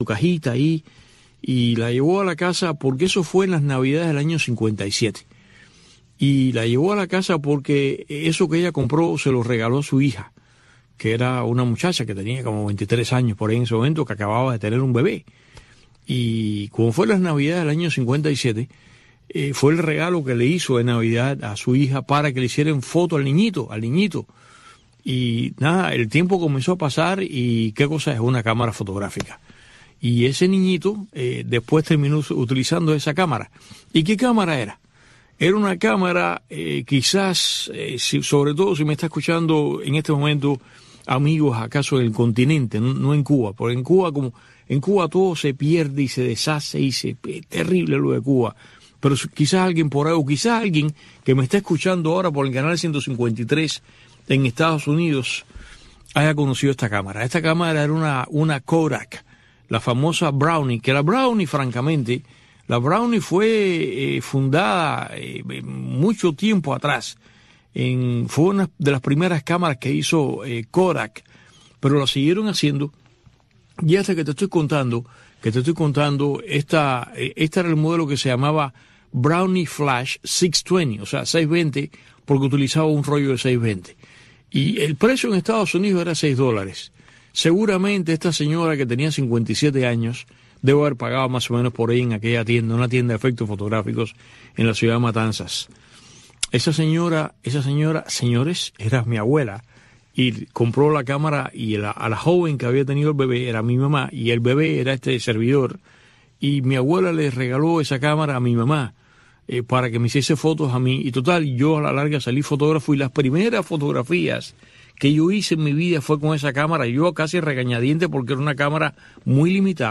conecta cada día con sus invitados en la isla en este espacio informativo en vivo